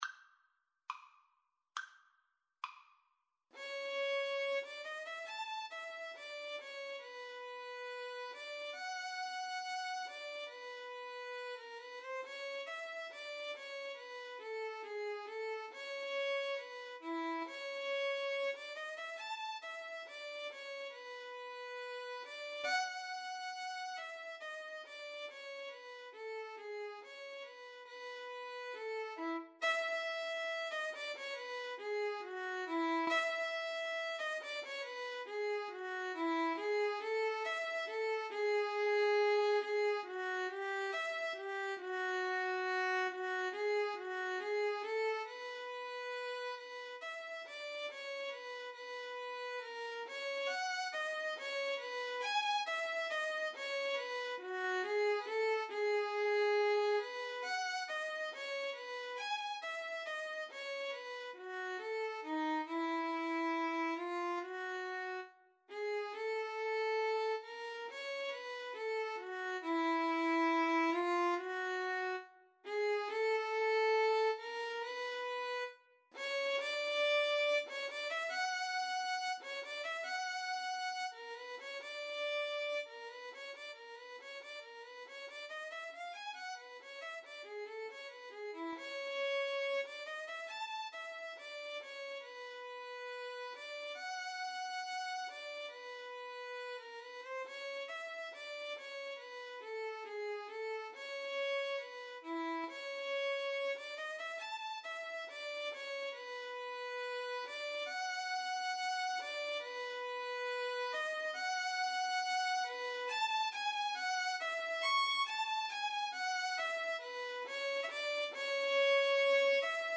2/4 (View more 2/4 Music)
~ = 69 Allegro grazioso (View more music marked Allegro)
Classical (View more Classical Violin Duet Music)